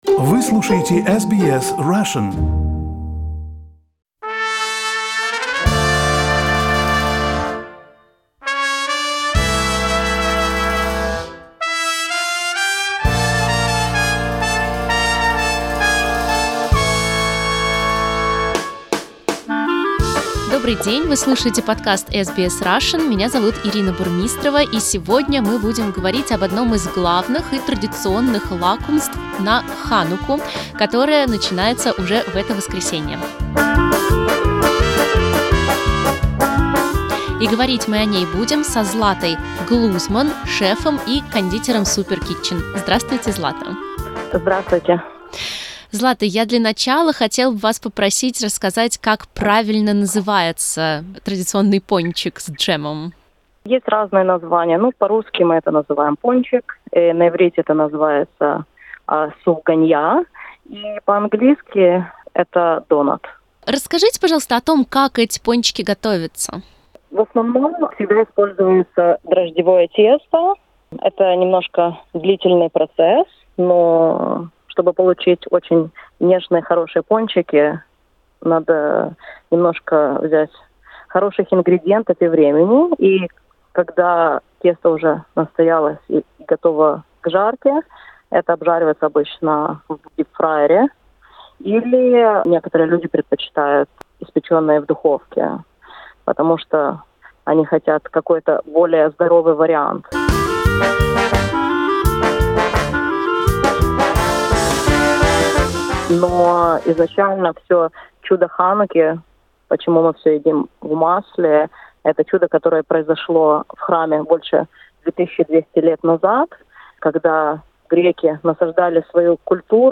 Гостья подкаста